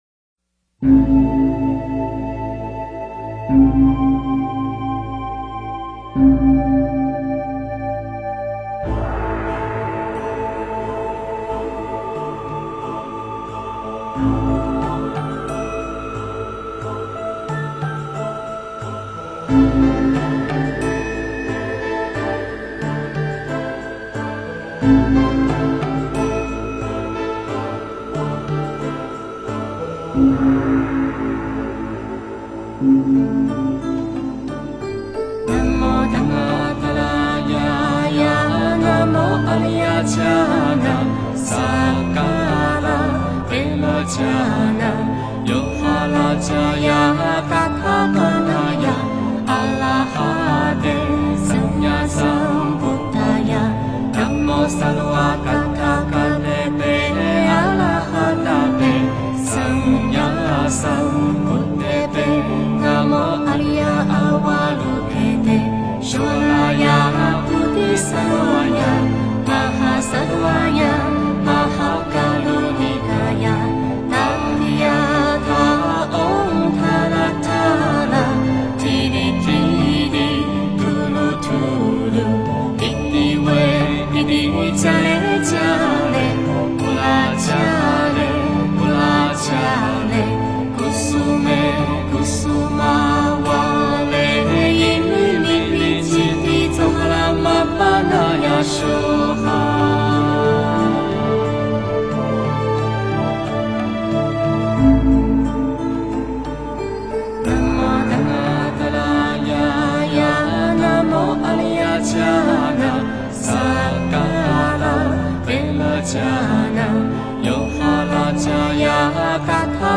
大悲咒(男女合颂版)
大悲咒(男女合颂版) 诵经 大悲咒(男女合颂版)--新韵传音 点我： 标签: 佛音 诵经 佛教音乐 返回列表 上一篇： 菩萨修学之十信位 下一篇： 观音菩萨颂 相关文章 Mind,Body&Spirit--Namast Mind,Body&Spirit--Namast...